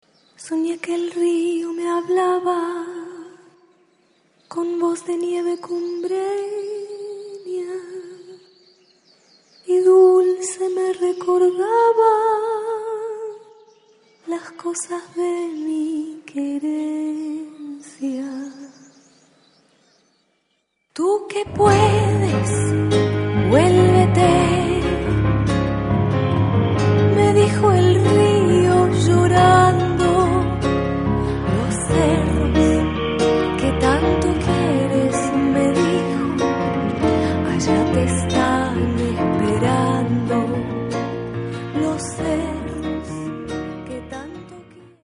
耽美的なメロディと官能的な歌声で注目を受けるアルゼンチン女性歌手
ダウンテンポ的なサウンドのなかに、チャランゴなどの演奏も加わった独創的な音世界。
スペイン語で歌われたまさに大人のためのラヴ・ソング集。